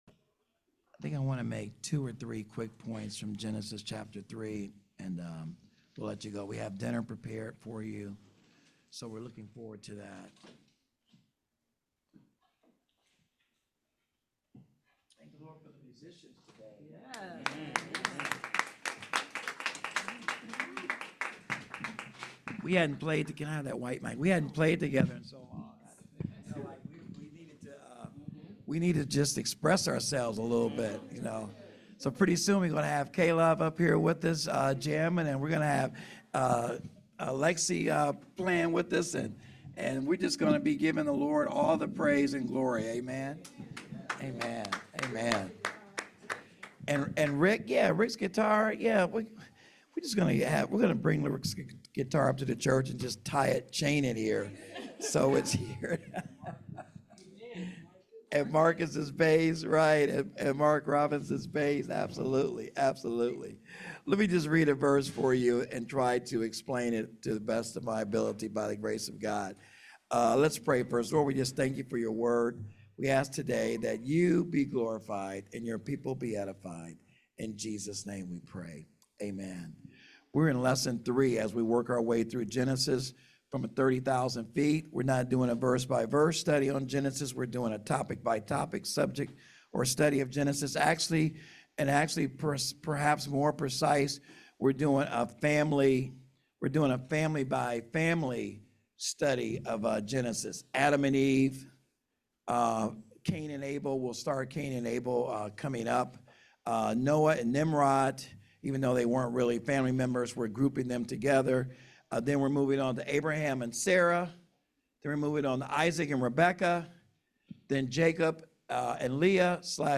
Sermon Handout